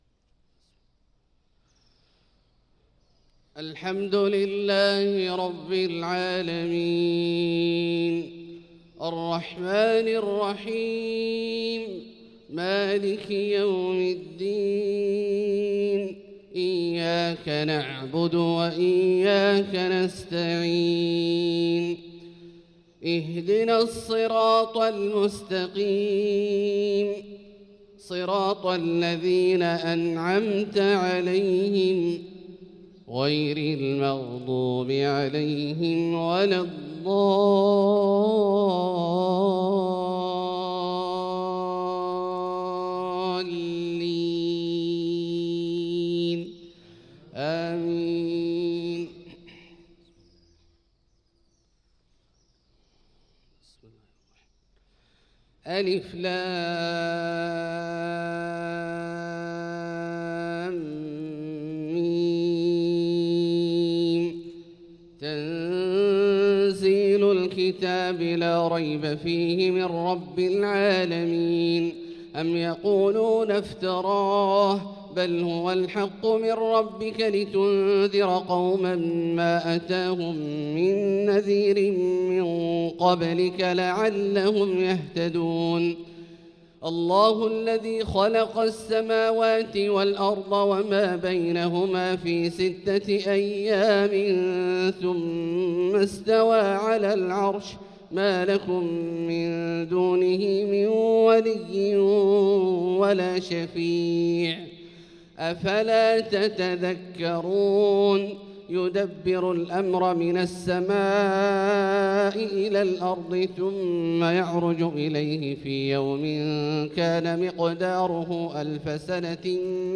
صلاة الفجر للقارئ عبدالله الجهني 6 شعبان 1445 هـ
تِلَاوَات الْحَرَمَيْن .